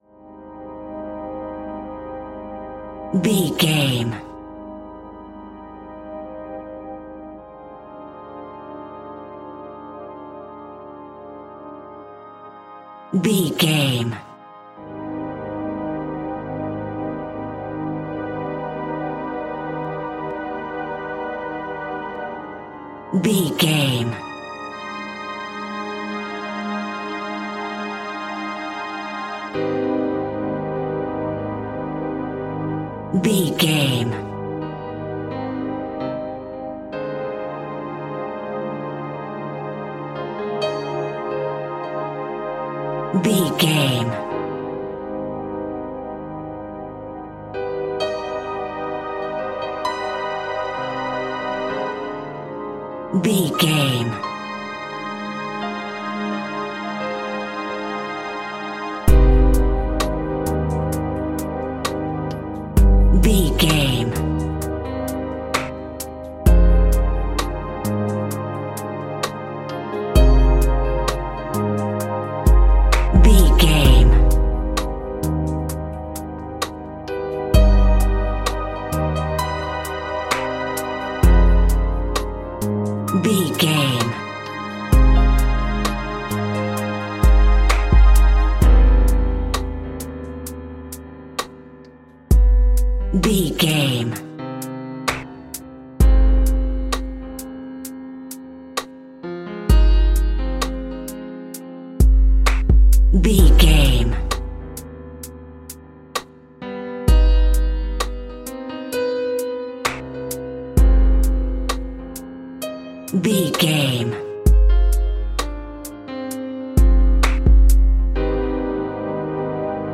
Aeolian/Minor
Slow
scary
tension
ominous
dark
suspense
eerie
industrial
strings
orchestra
percussion
piano
synthesiser
Horror synth
Horror Ambience
electronics